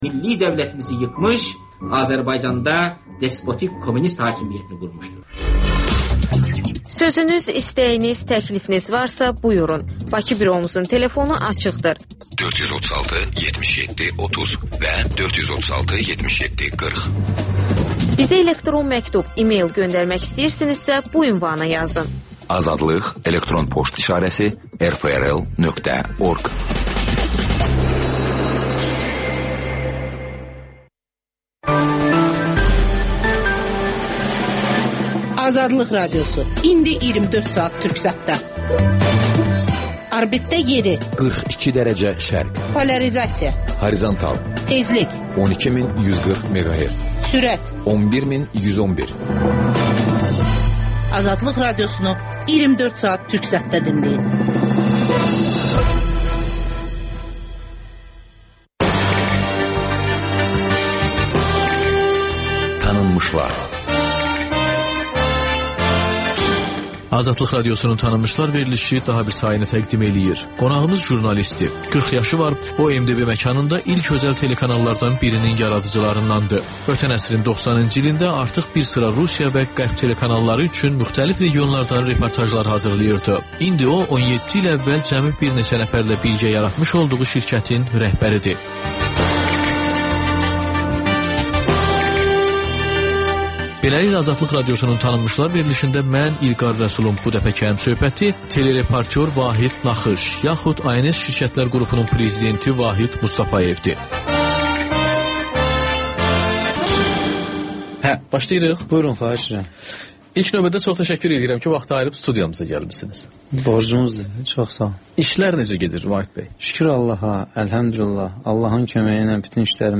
Ölkənin tanınmış simaları ilə söhbət Təkrar